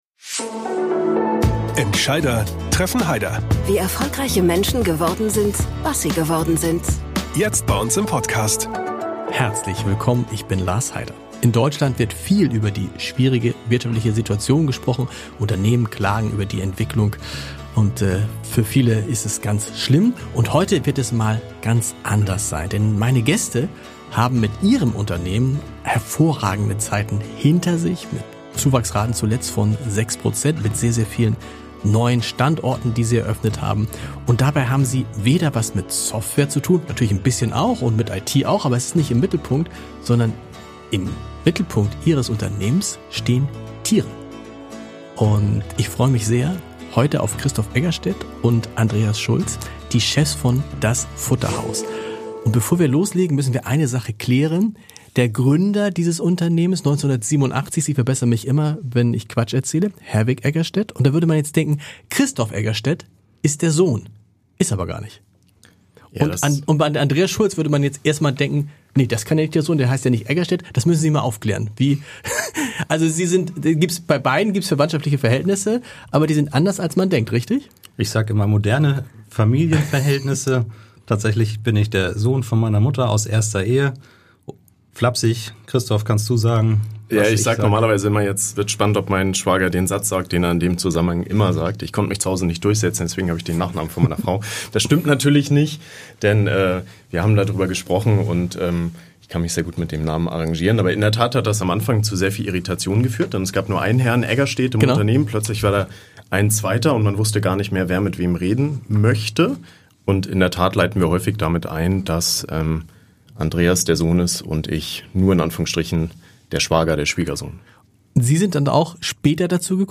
# Interview